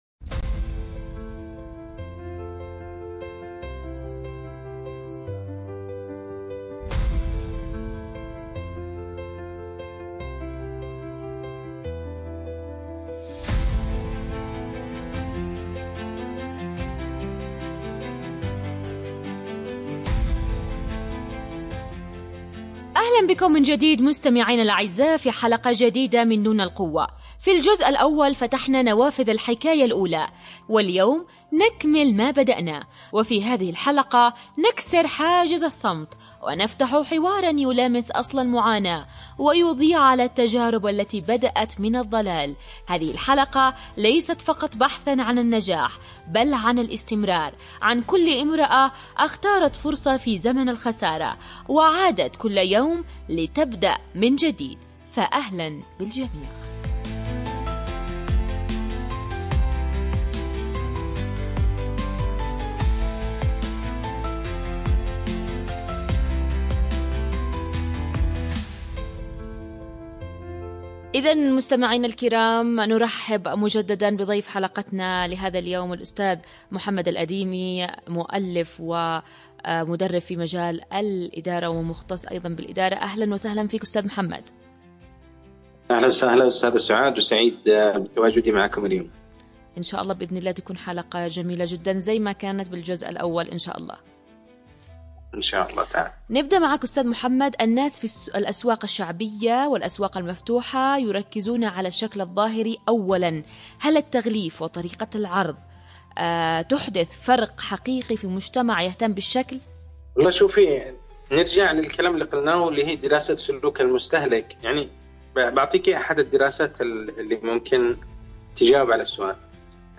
📻 المكان: عبر أثير إذاعة رمز